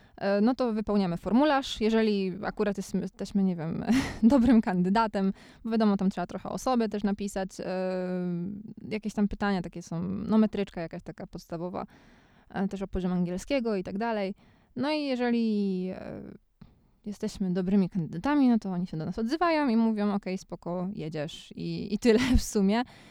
• Female: Conversation
Kobieta: Rozmowa
ID009_conversation.wav